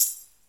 normal-slidertick.ogg